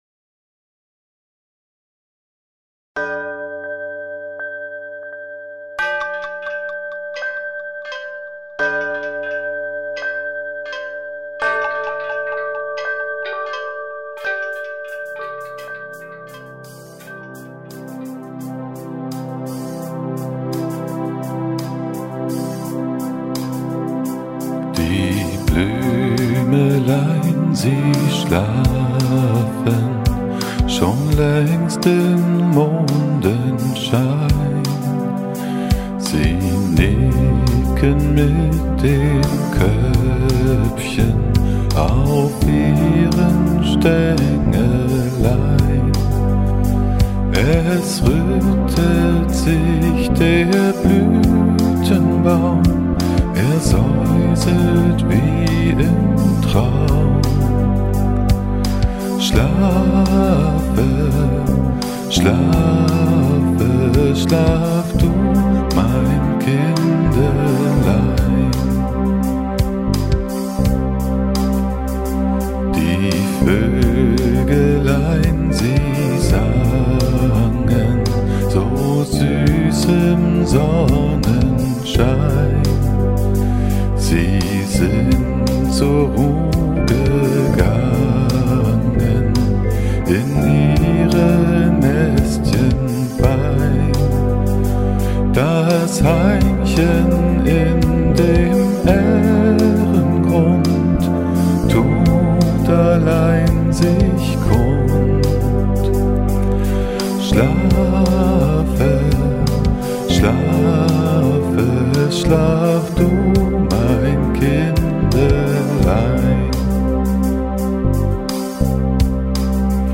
kinderlieder mit erwachsenem sound
hallo zusammen, zum feste kam meine freundin auf die idee, die kinderchen in der verwandtschaft mit selbst aufgenommenen kinderliedern zu beschenken.
schlaflied